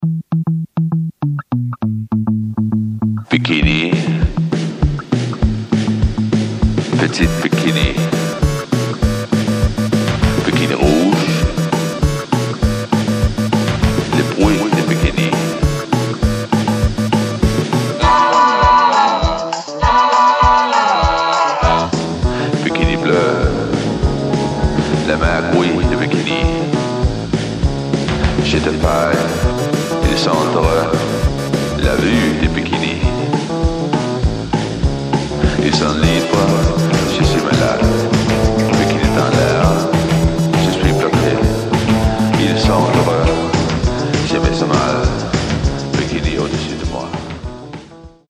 analoge Keyboards, Synthies und Effektgeräte
klassischem 60s Frauengesang